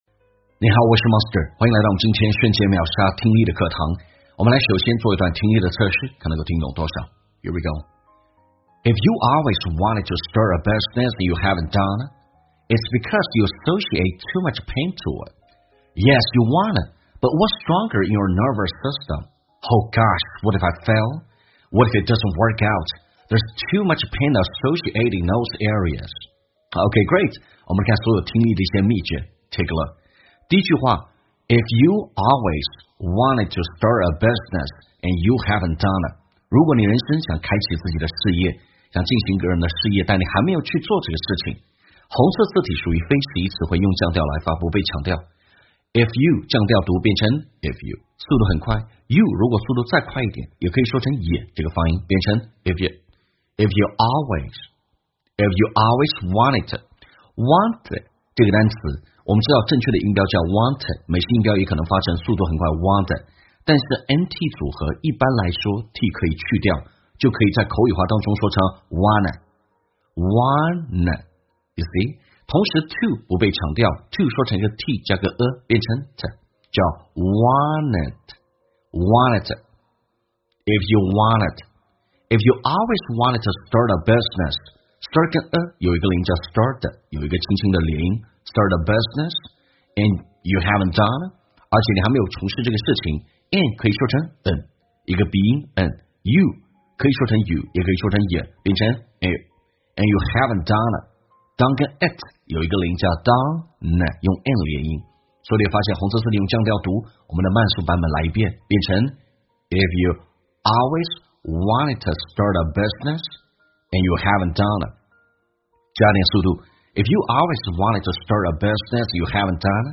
在线英语听力室瞬间秒杀听力 第373期:万一我失败了怎么办呀的听力文件下载,栏目通过对几个小短句的断句停顿、语音语调连读分析，帮你掌握地道英语的发音特点，让你的朗读更流畅自然。